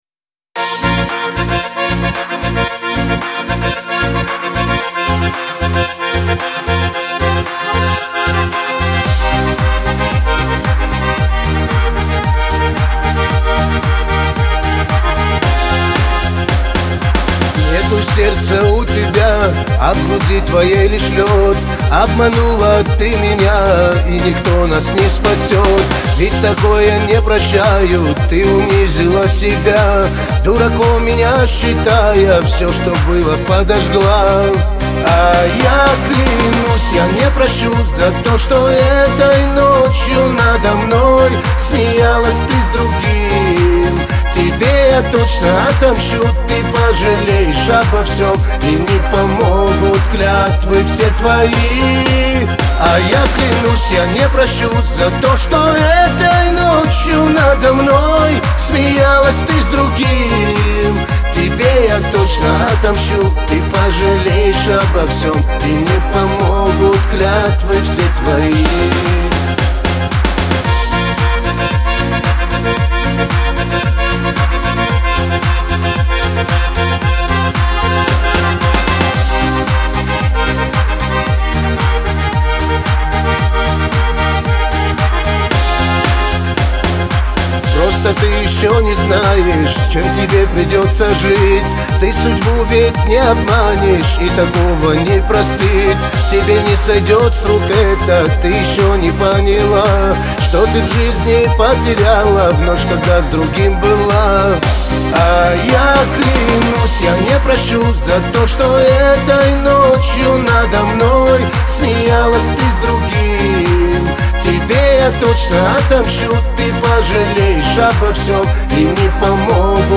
Кавказ – поп